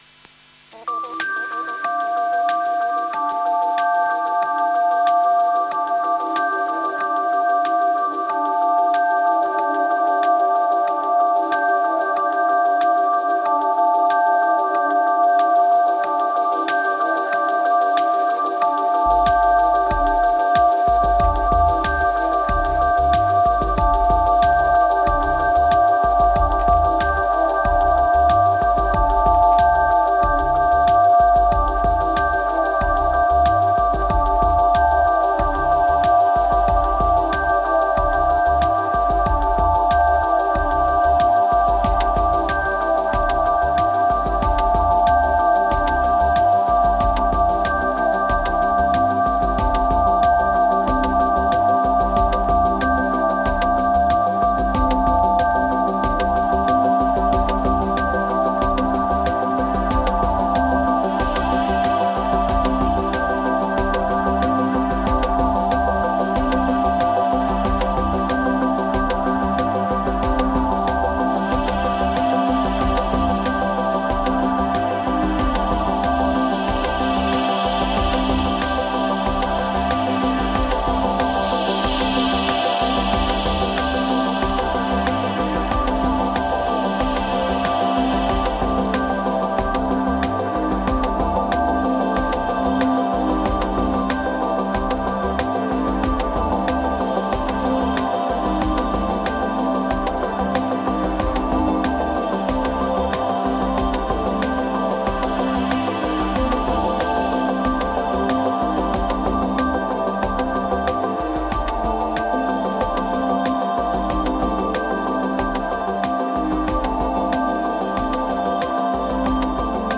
Ambient beats, MPC X. Tired condition, lower moods.